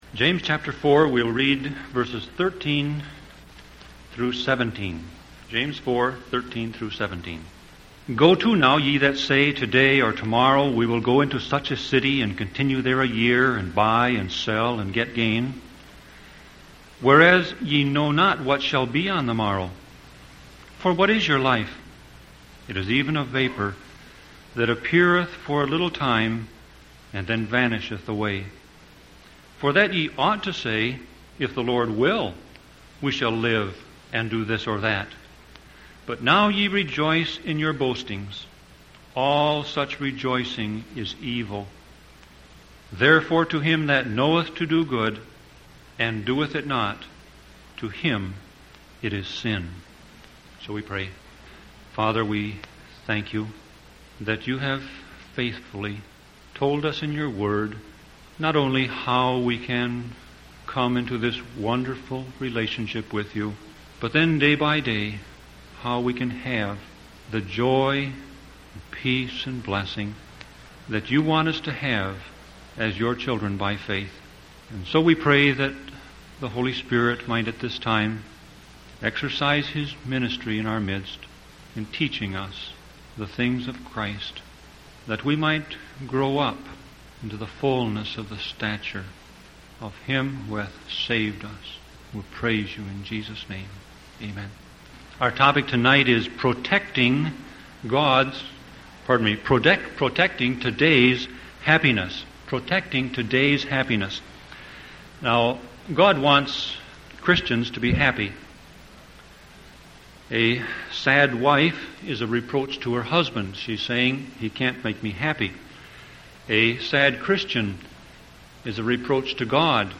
Sermon Audio Passage: James 4:13-17 Service Type